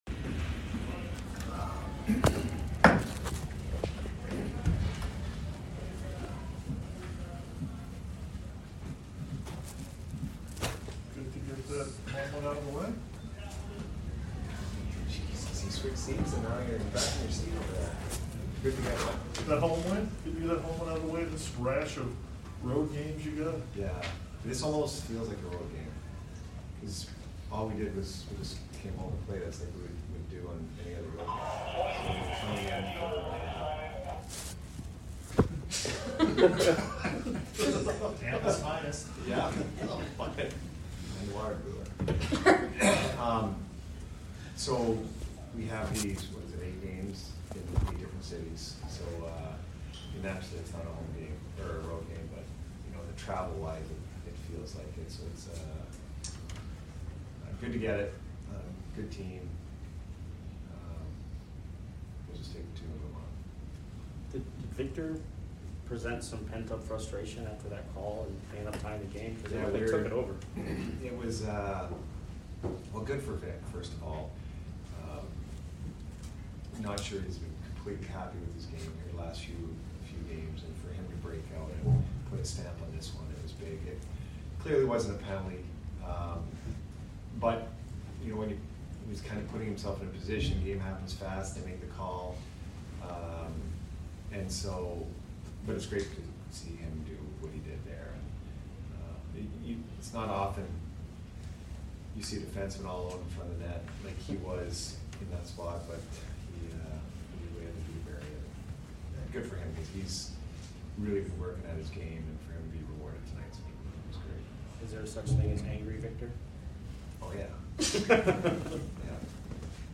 Head Coach Jon Cooper Post Game Vs STL 12/2/21